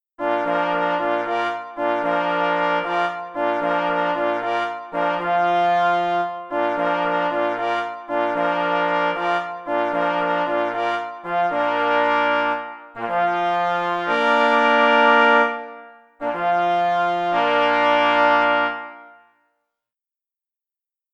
P. Grzywacz – Bóbr na rozkładzie – 2 plesy i 2 parforsy | PDF